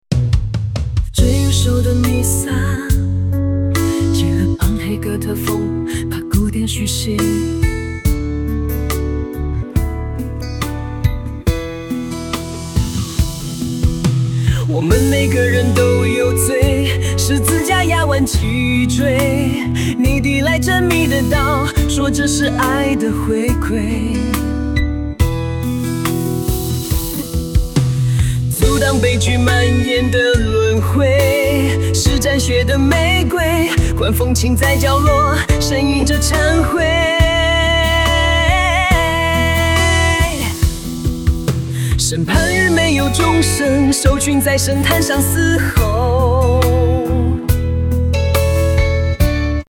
*——暗黑哥特风×古典叙事——*
人工智能生成式歌曲